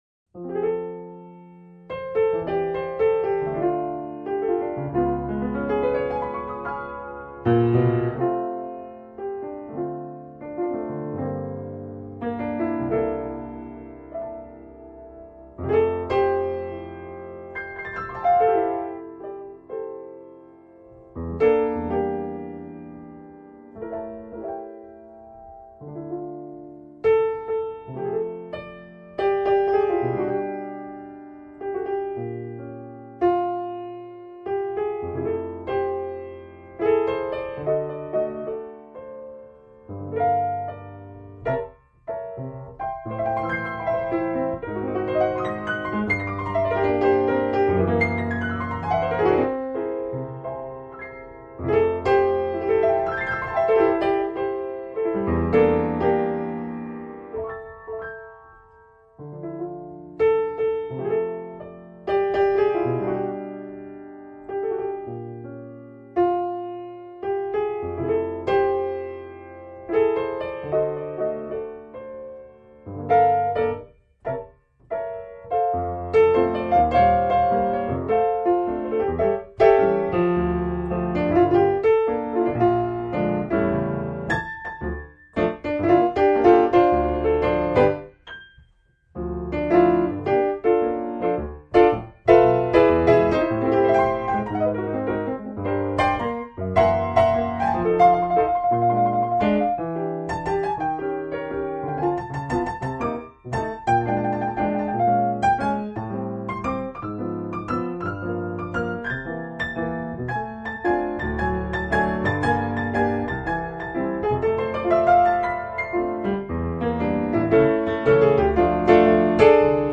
Barpiano
seriöses, unaufdringliches und musikalisch hochklassiges Barpianospiel
A kind of Boogie